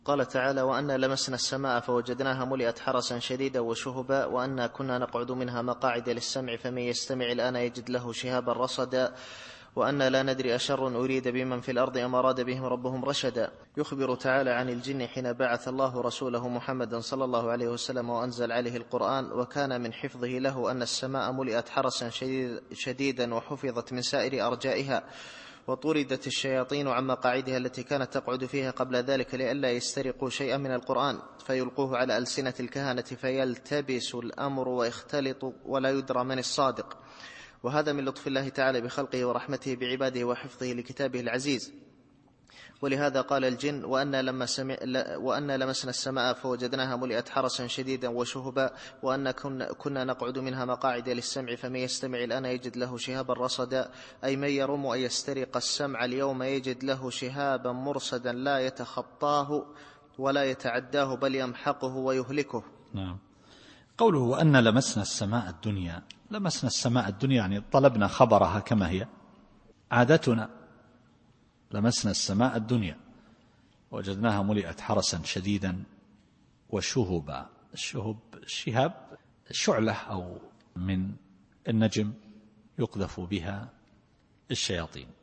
التفسير الصوتي [الجن / 8]